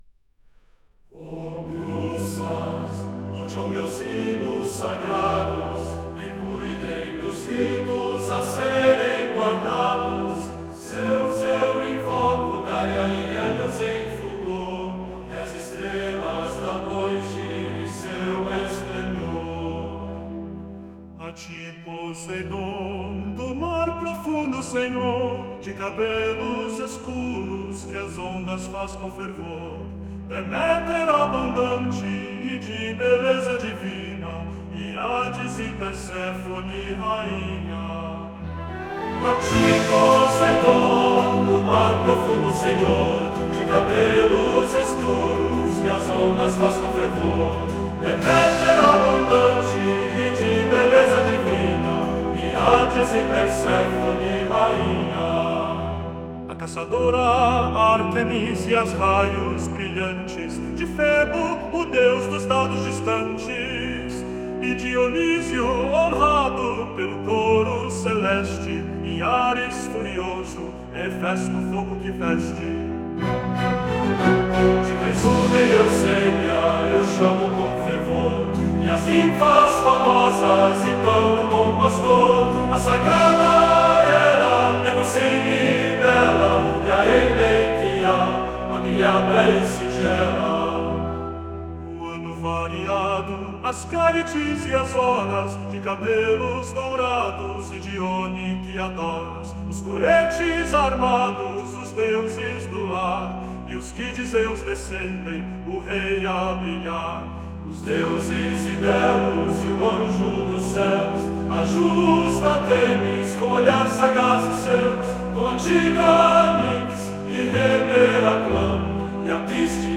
accustic, ancient greek, lyre, chorus
Versão Musicada